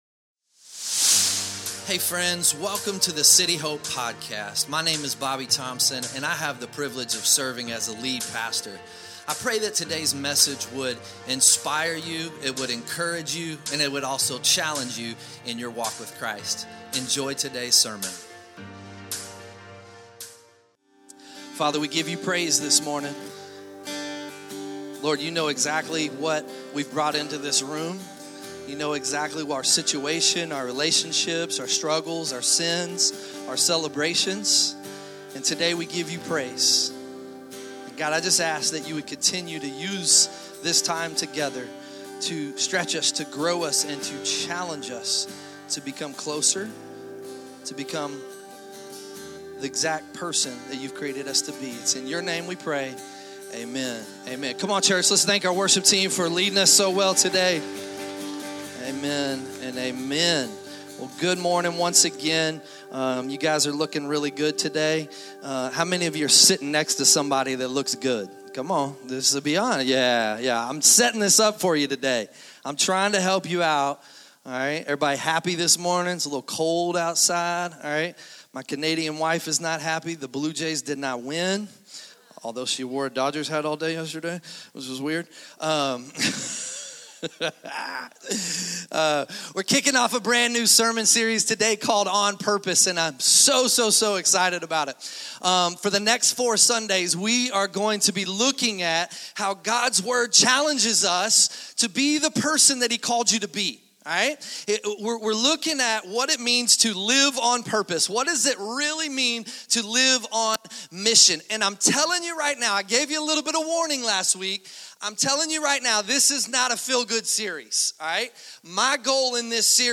2025 Sunday Morning Church…